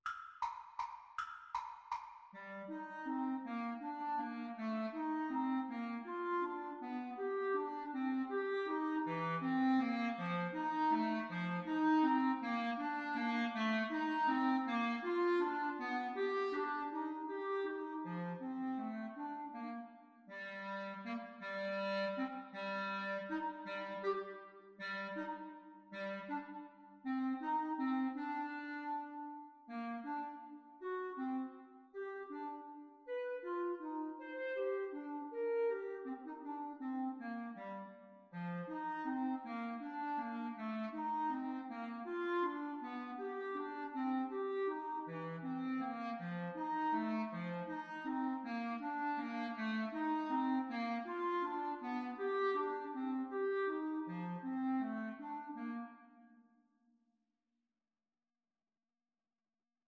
Play (or use space bar on your keyboard) Pause Music Playalong - Player 1 Accompaniment reset tempo print settings full screen
3/4 (View more 3/4 Music)
Non troppo presto
Bb major (Sounding Pitch) C major (Clarinet in Bb) (View more Bb major Music for Clarinet Duet )
Classical (View more Classical Clarinet Duet Music)